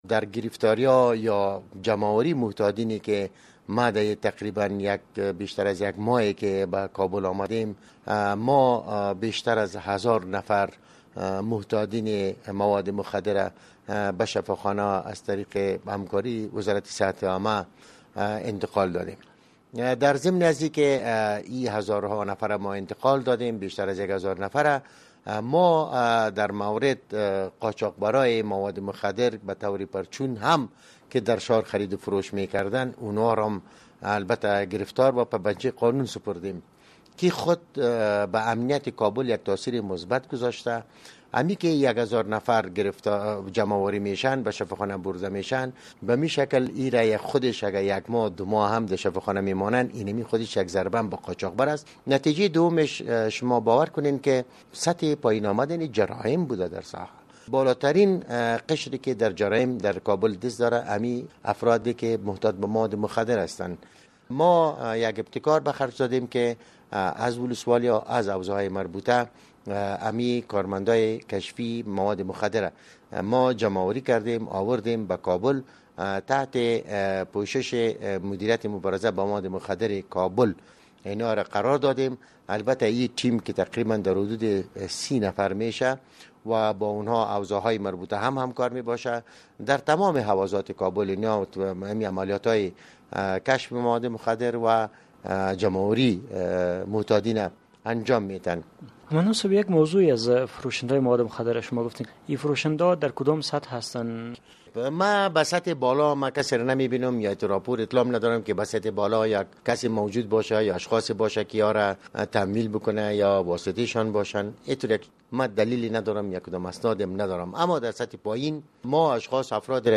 با داوود امین، قوماندان امنیه کابل مصاحبه کرده و در ابتدا از وی در رابطه به تاثیرات کار و بار مواد مخدر بر وضعیت امنیتی این شهر پرسیده‌است.